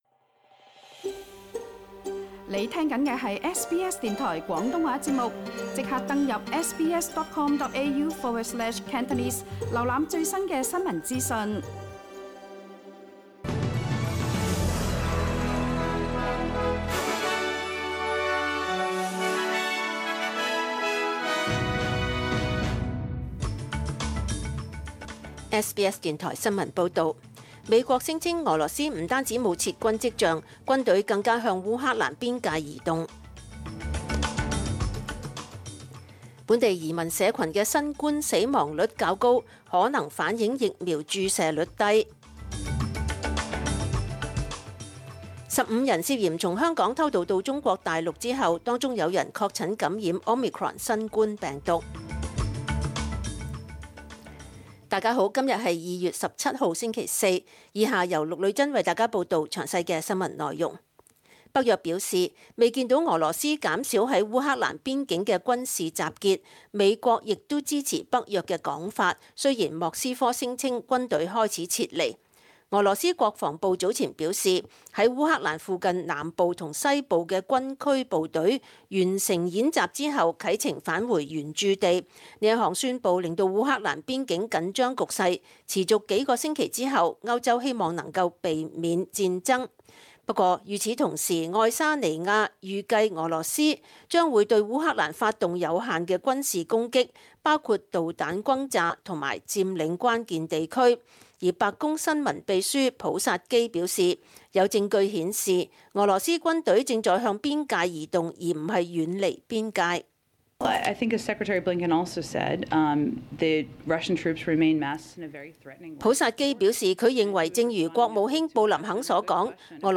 SBS 中文新聞 （2月17日）